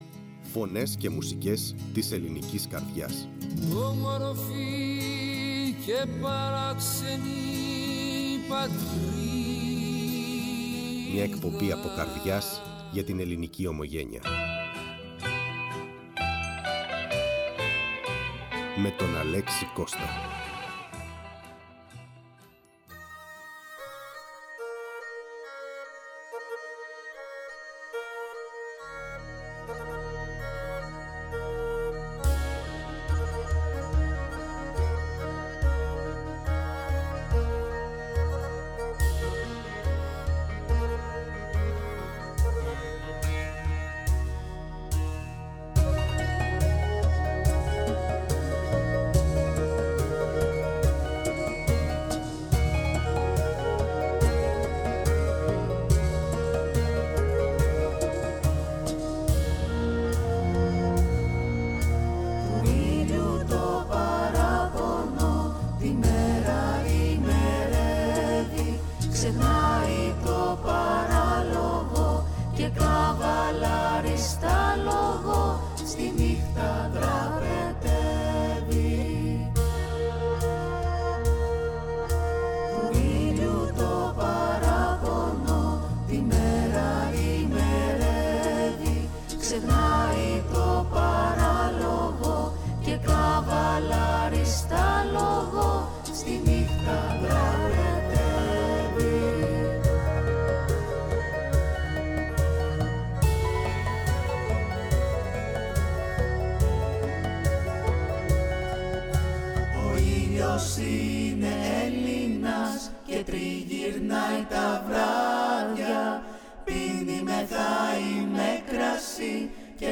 ο οποίος μας μίλησε τηλεφωνικά